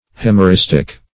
Euhemeristic \Eu*hem`er*is"tic\, a. Of or pertaining to euhemerism.